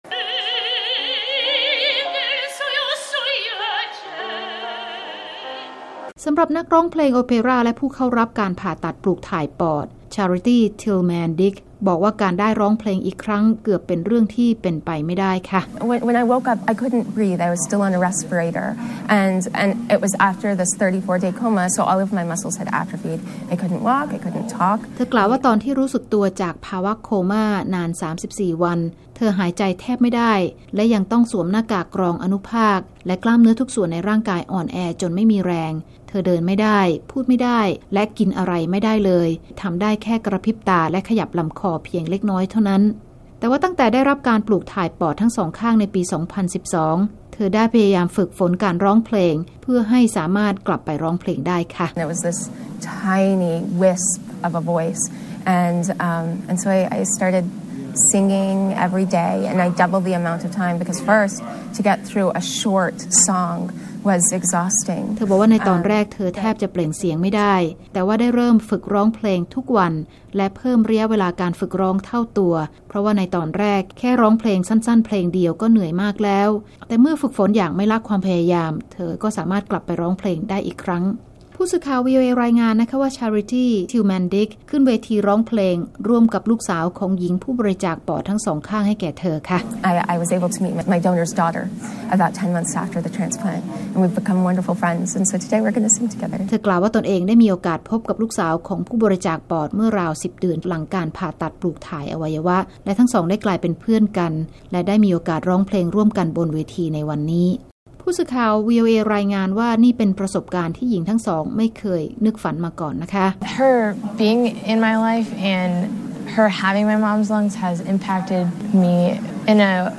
หญิงทั้งสองคนได้ร้องเพลง "American Rainbow" ร่วมกันเป็นครั้งเเรกบนเวทีในงานประชุม Medical Innovation Summit ประจำปี ต่อหน้าบรรดาแพทย์และผู้เข้าร่วมงานหลายร้อยคนเมื่อเร็วๆ นี้